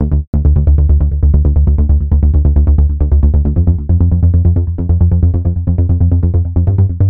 回声Trance Bass Progression Loop
描述：带有延迟和混响的Echoey Trance低音合成器，调子是D小调，循环形成4小节的进展，与奶油低音样本的模式相同。
Tag: 135 bpm Trance Loops Bass Loops 1.20 MB wav Key : Unknown